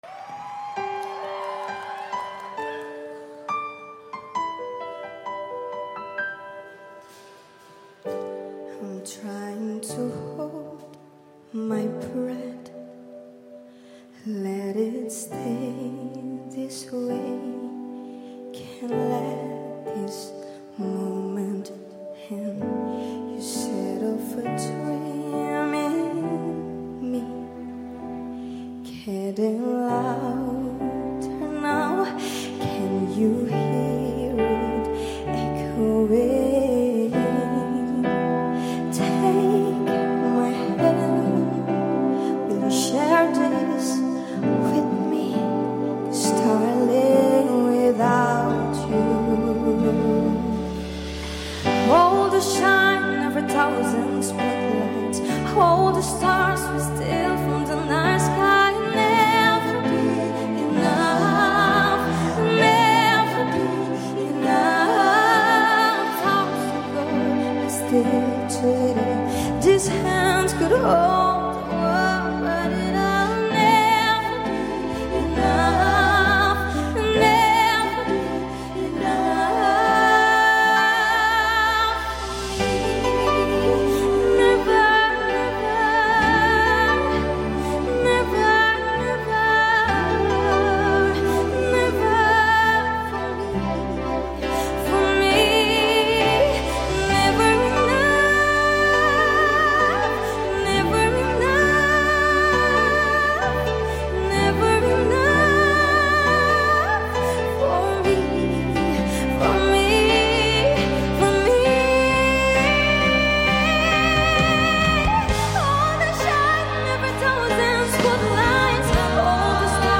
angel voice 👍👍👍
versi lembut dan mulusnya nih enak di denger adem